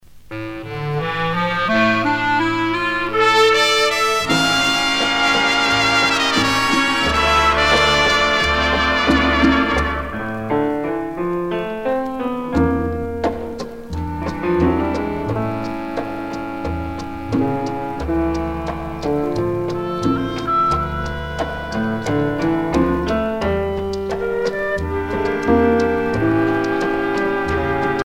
danse : boléro
Pièce musicale éditée